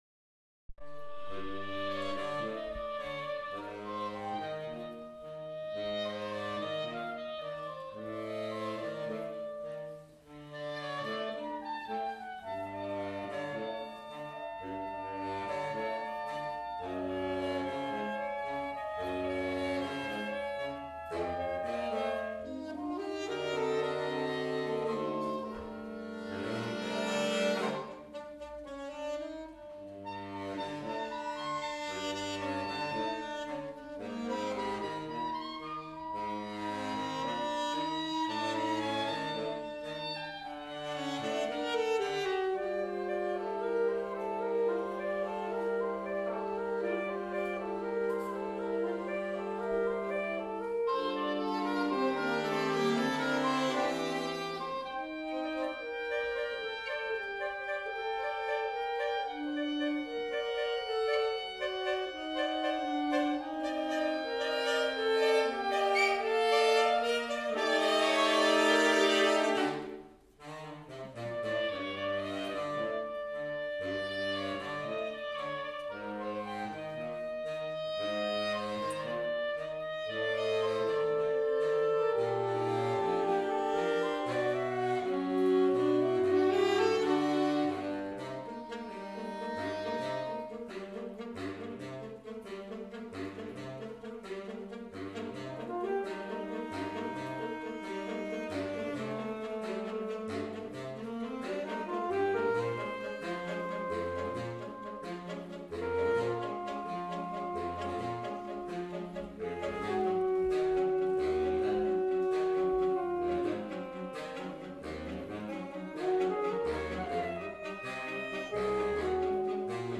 Saxophone Quintet - Tango
A Concert of Wind, Brass and Percussion, April 2015